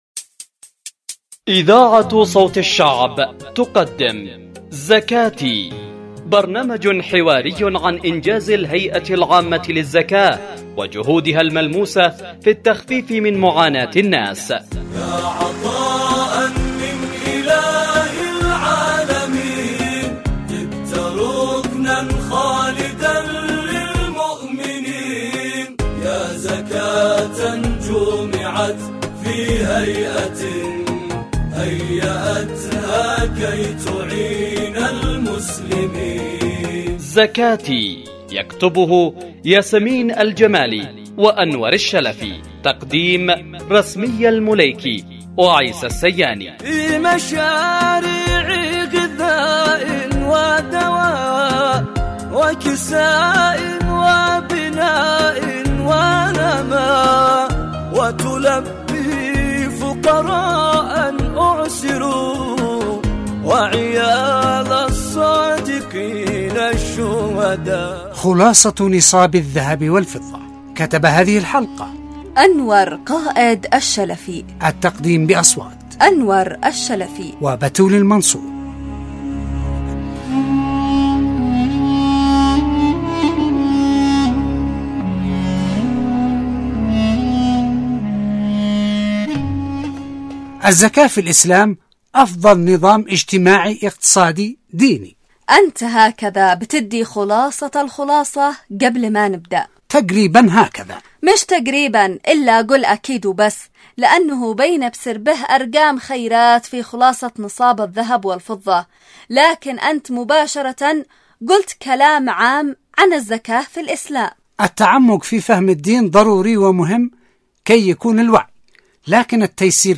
البرامج الحوارية